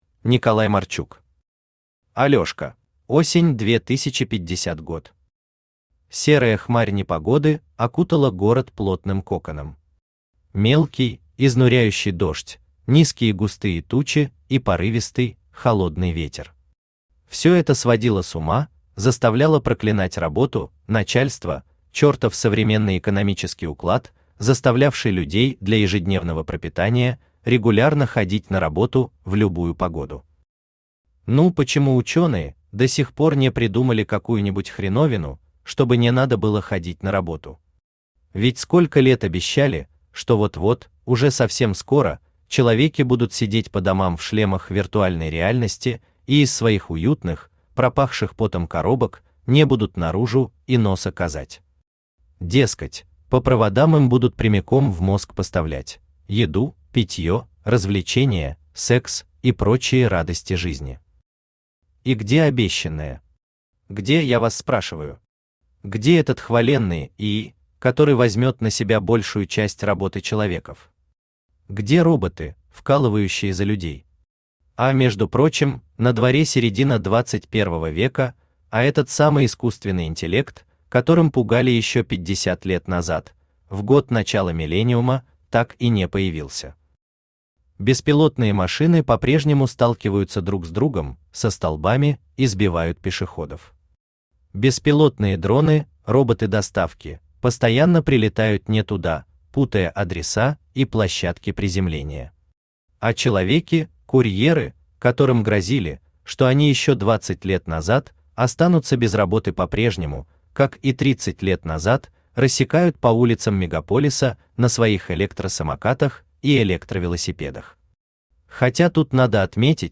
Аудиокнига Алёшка | Библиотека аудиокниг
Aудиокнига Алёшка Автор Николай Марчук Читает аудиокнигу Авточтец ЛитРес.